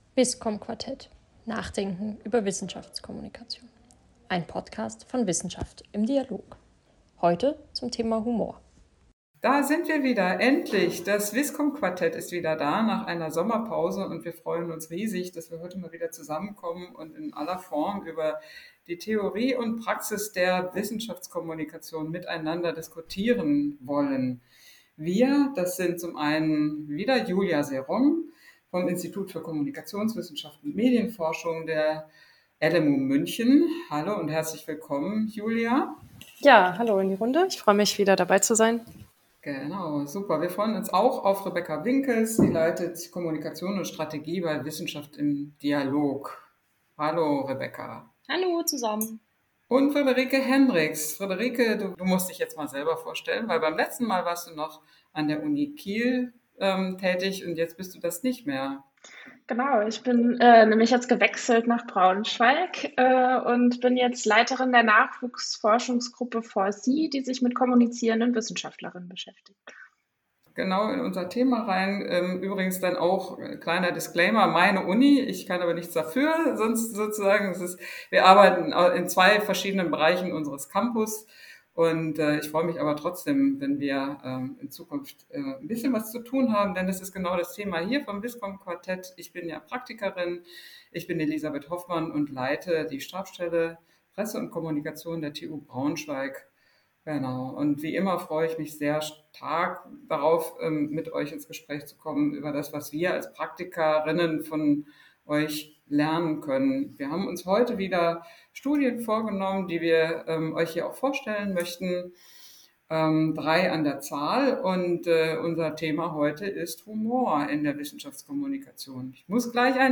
Das Wisskomm-Quartett diskutiert drei aktuelle Publikationen, die sich mit der Wirkung von Humor in der Wissenschaftskommunikation befassen. Sie vergleichen die Wirkung von Videos mit und ohne Publikumsgelächter.